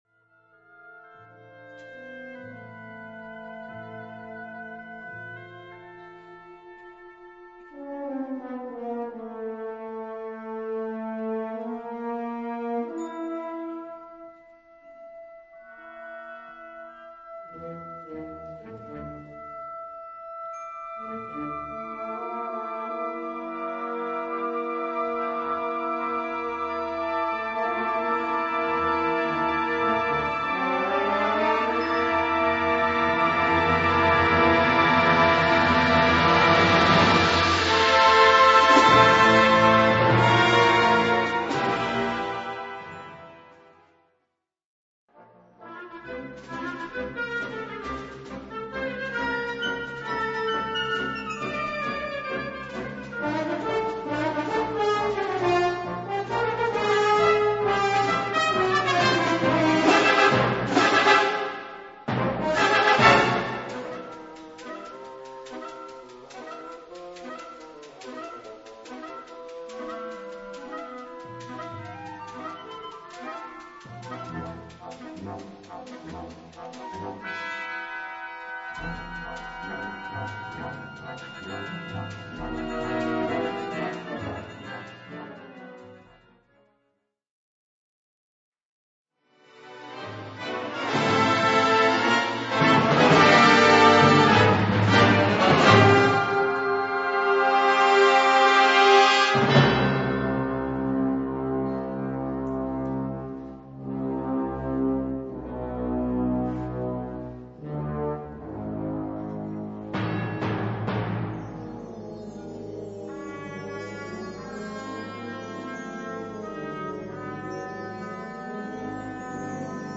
Categorie Harmonie/Fanfare/Brass-orkest
Subcategorie Hedendaagse blaasmuziek (1945-heden)
Bezetting Ha (harmonieorkest)
Het werk begint met een leidmotief gespeeld door een fagot.